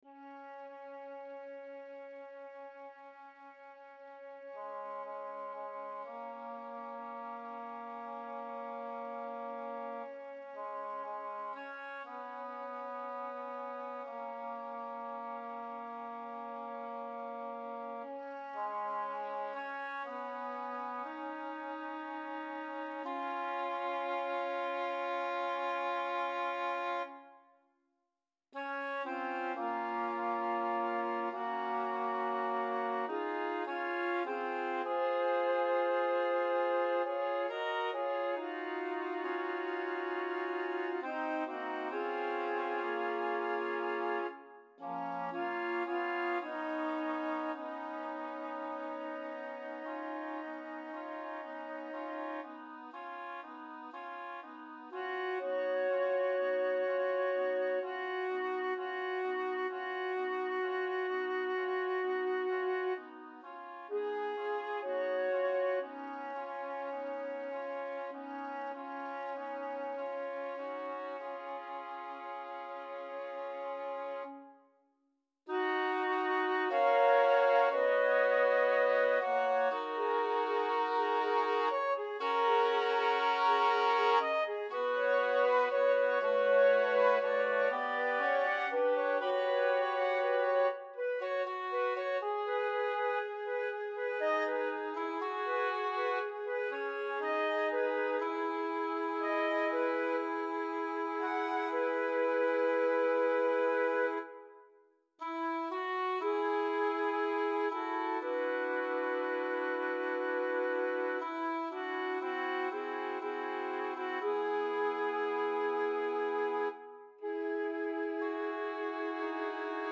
SSAA a cappella
This bright, joyous setting